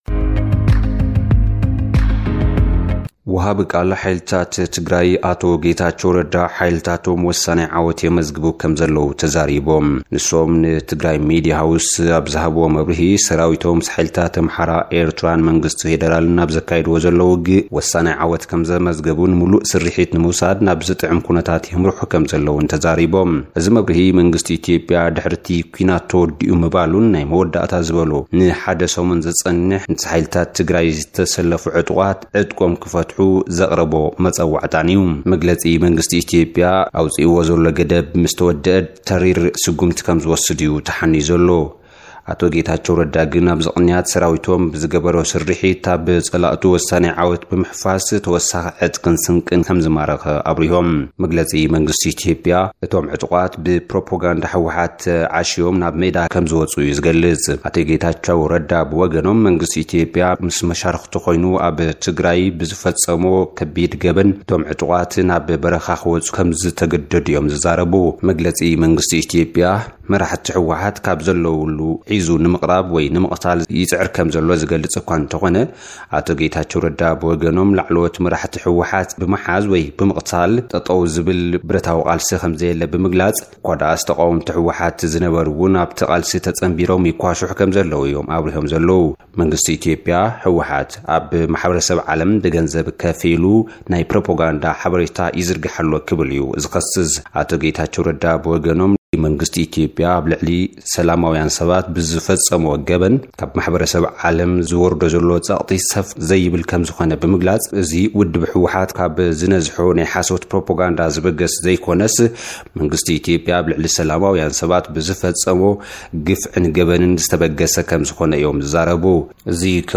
ሓጸርቲ ጸብጻባት፡